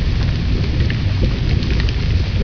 uLava1.ogg